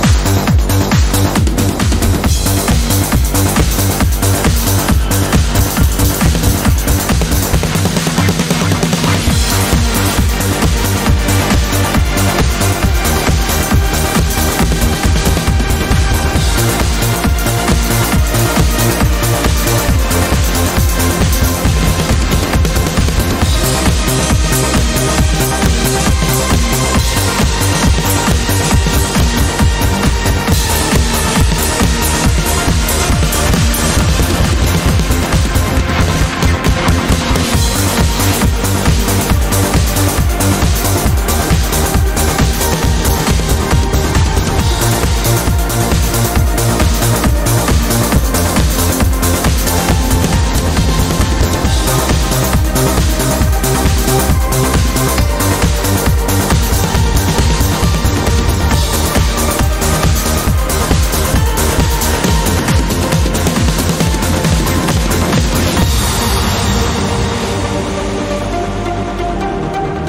Wie heißt der Song (Trance)?
Da bin ich jetzt auch am überlegen.....Klingt schon ein bisschen so, als wenn das Stück schon ganz schön alt ist, kommt mir aber auch irgendwie bekannt vor.
Ich habe vor ein paar Jahren mal einen Song in einem Stream gehört. Diesen Song hätte ich gerne in voller Länge, Qualität und ohne Störgeräusche.